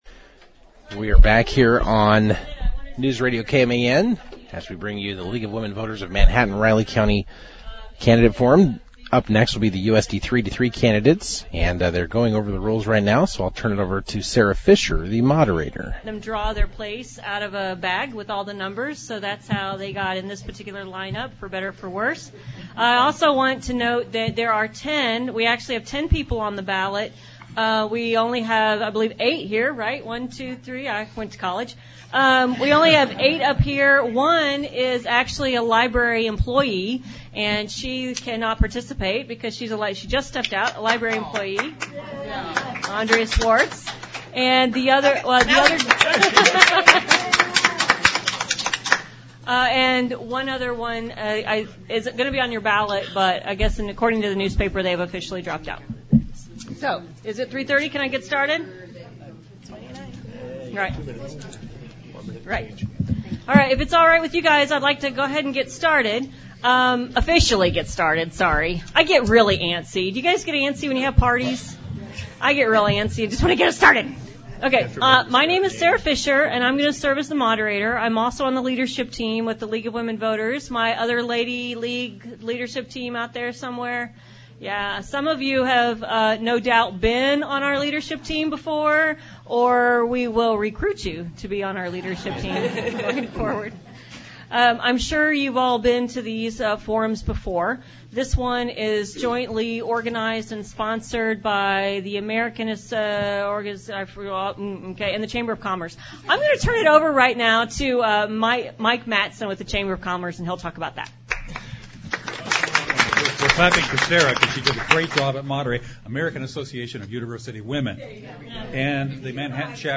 The League of Women’s Voters held two candidate forums at the Public Library.
Time stamps below indicate when in the audio questions are asked of the candidates, who then self identify before providing their 90 second responses to each question.